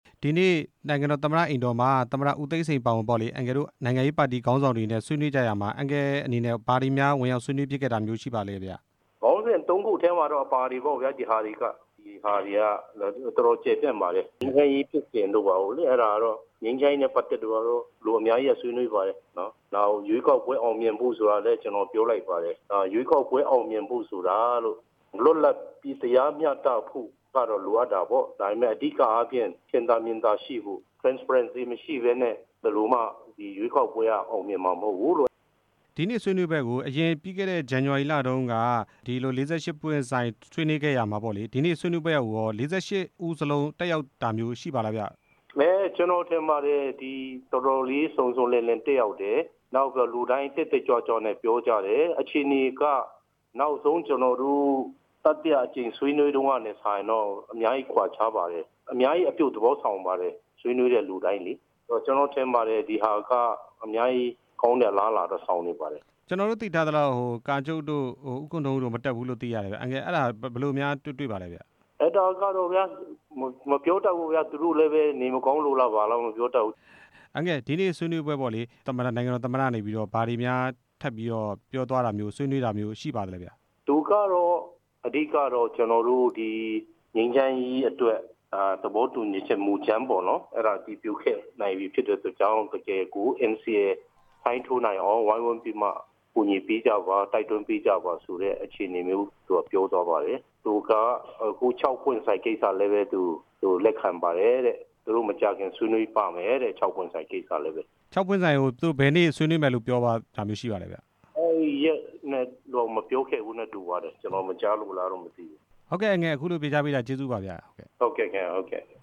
သမ္မတနဲ့ ထိပ်သီး ၄၂ ဦး တွေ့ ဆုံပွဲအကြောင်း မေးမြန်းချက်